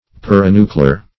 Search Result for " perinuclear" : The Collaborative International Dictionary of English v.0.48: Perinuclear \Per`i*nu"cle*ar\, a. (Biol.) Of or pertaining to a nucleus; situated around a nucleus; as, the perinuclear protoplasm.